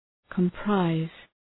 Προφορά
{kəm’praız}